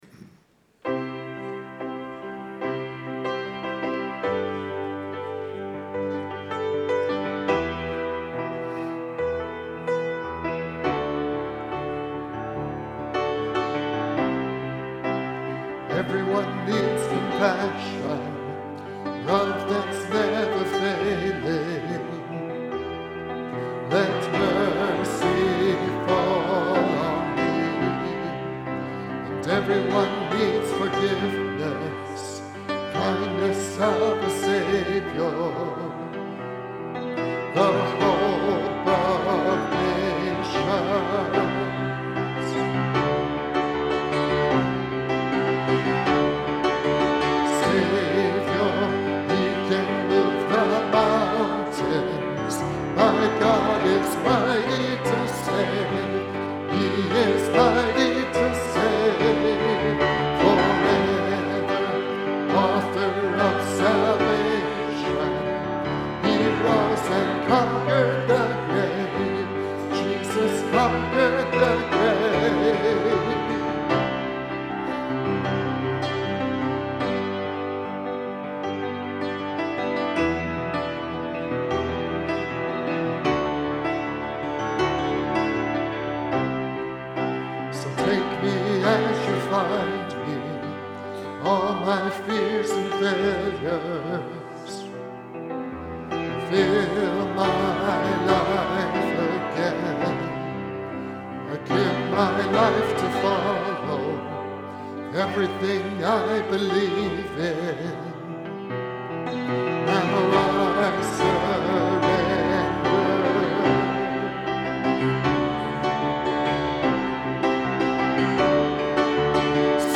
Bible Text: Philippians 2:12-18 | Preacher